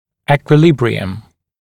[ˌiːkwɪ’lɪbrɪəm] [ˌekwɪ’-][ˌи:куи’либриэм], [ˌэкуи’-]равновесие, баланс